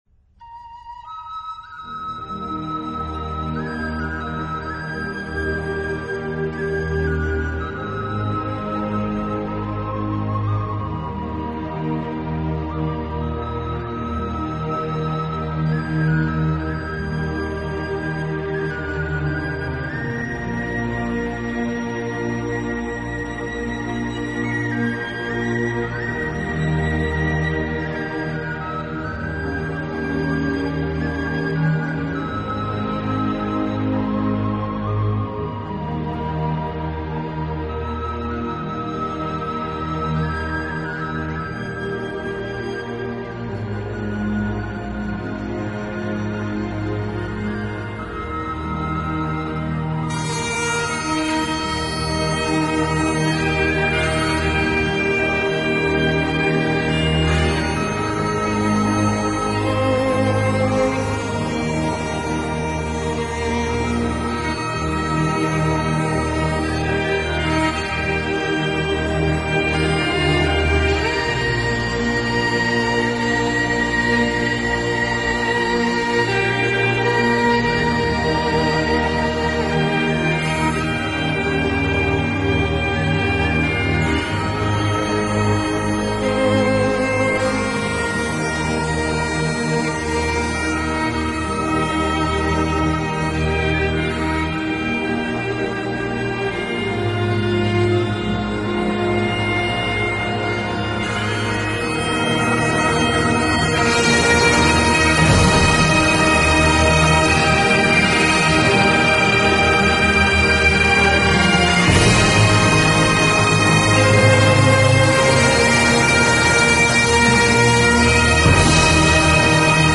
音乐类型：新世纪